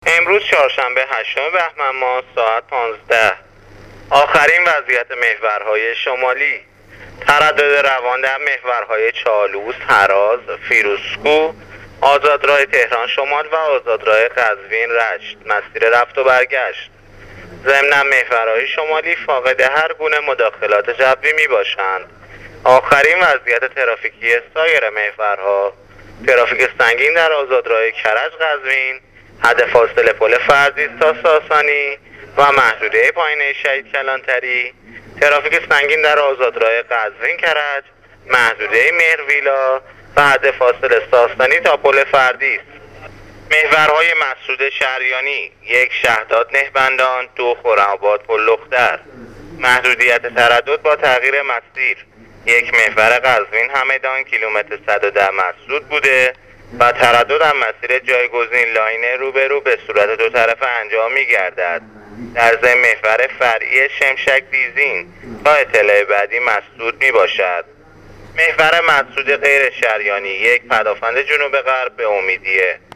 گزارش رادیو اینترنتی از آخرین وضعیت ترافیکی جاده‌ها تا ساعت ۱۵ هشتم بهمن